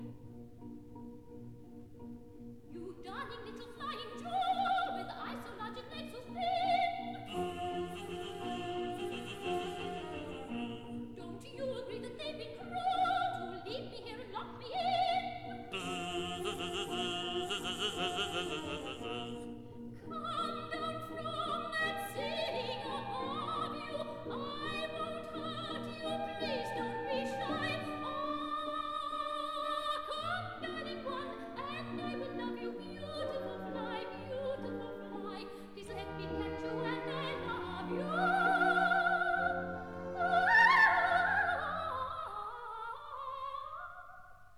1960 stereo recording